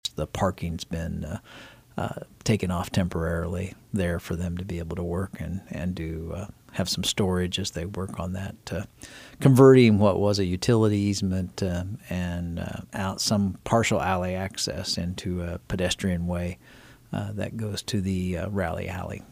Fehr said that demolition in the alley between Rally House and Goodcents has been completed.